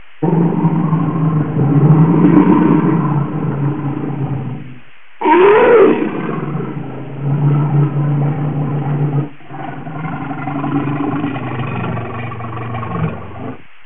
bear-a.wav